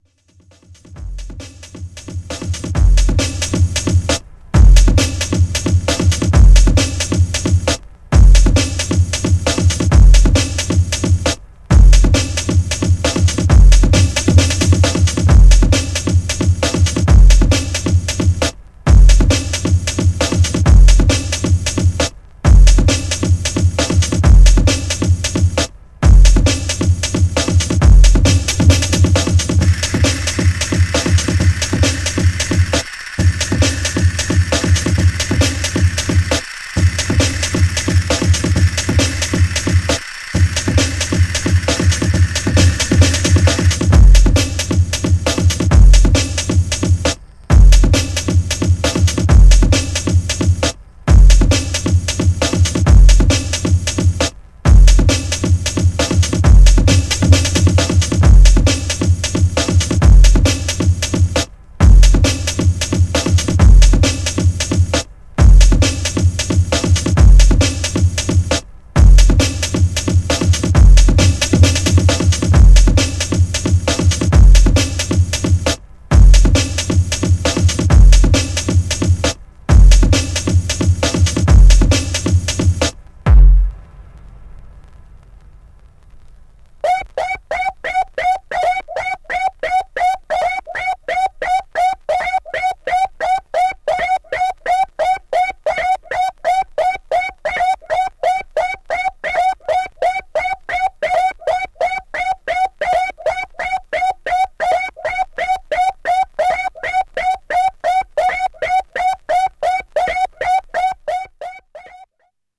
Styl: House, Breaks/Breakbeat